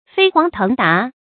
注音：ㄈㄟ ㄏㄨㄤˊ ㄊㄥˊ ㄉㄚˊ
讀音讀法：
飛黃騰達的讀法